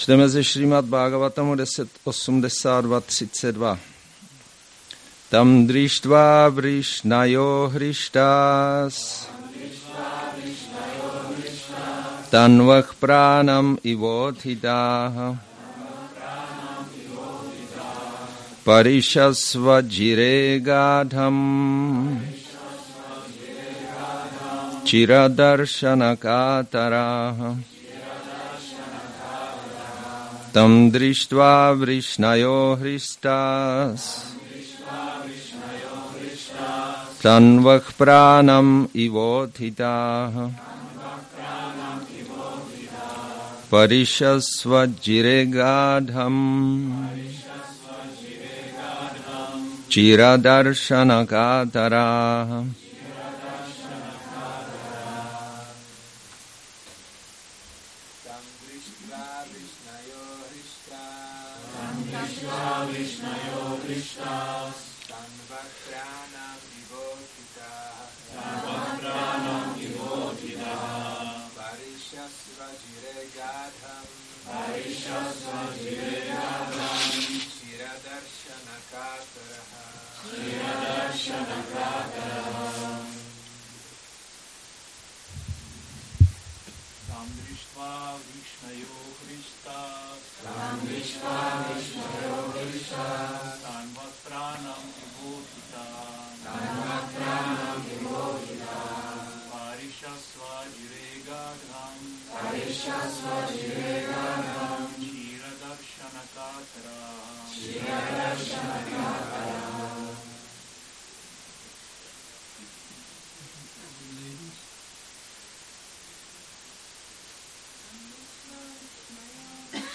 Šrí Šrí Nitái Navadvípačandra mandir
Přednáška SB-10.82.32